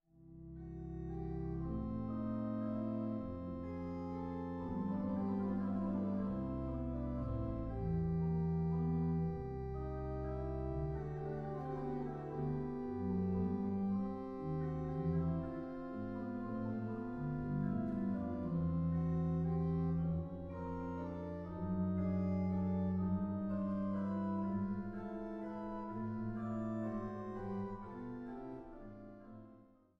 Reichenbach/St. Peter und Paul